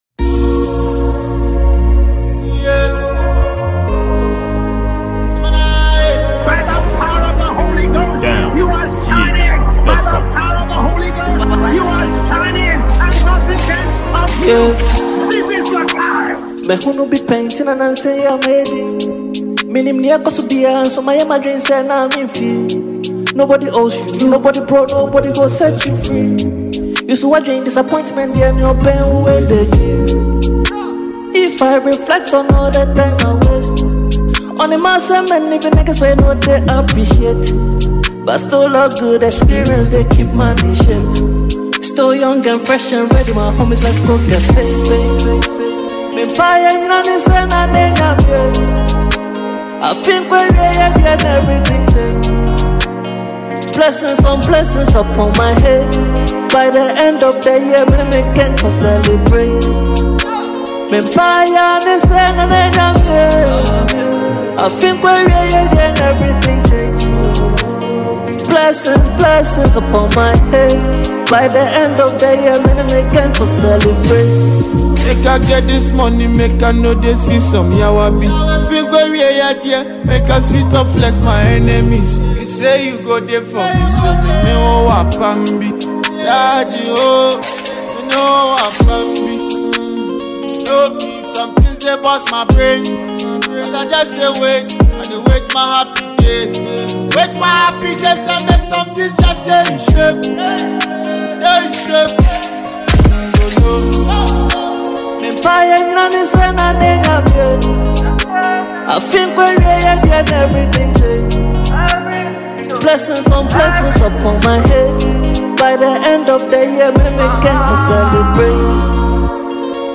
Ghanaian Afrobeat highlife singer and songwriter